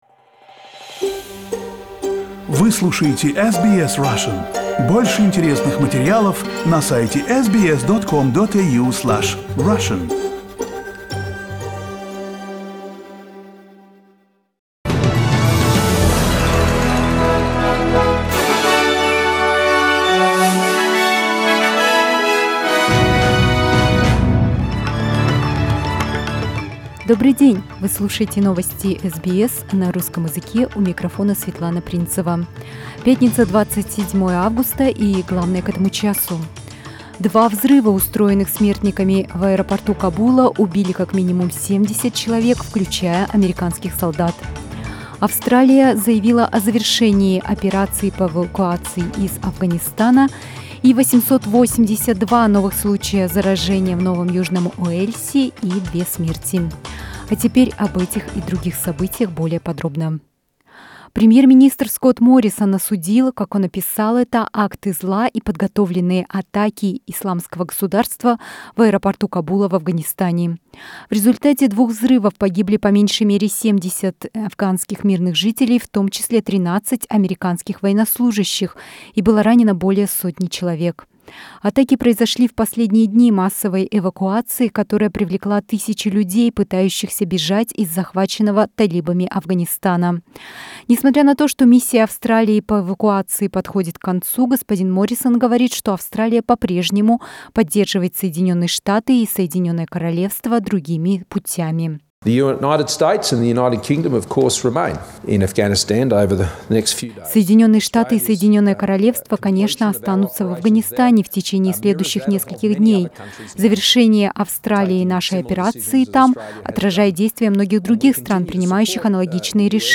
Новости SBS на русском языке - 27.08